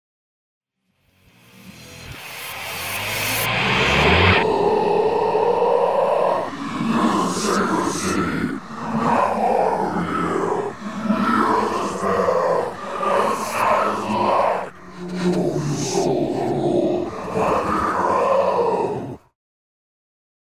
messaggio subliminale verbale
death metal
Tipo di backmasking Rovesciato